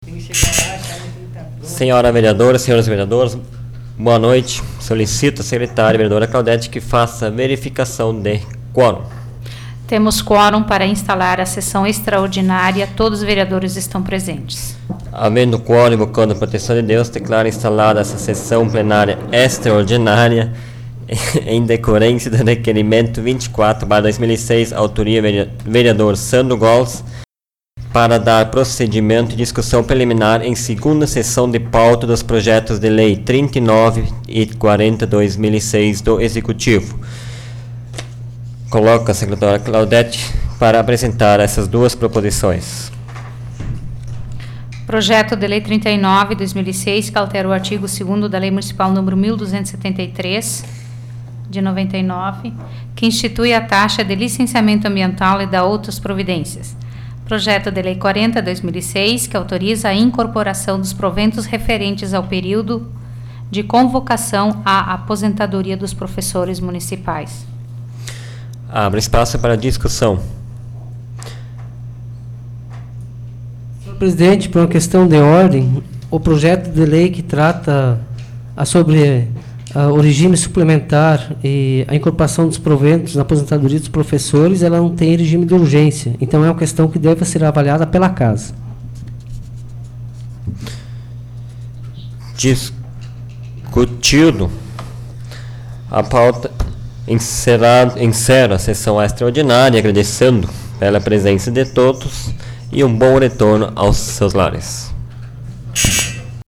Áudio da 28ª Sessão Plenária Extraordinária da 12ª Legislatura, de 18 de dezembro de 2006